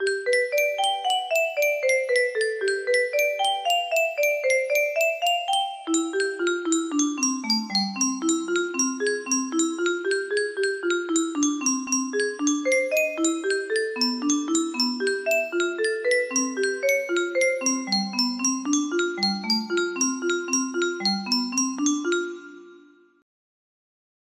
MELODIA CLÁSICA music box melody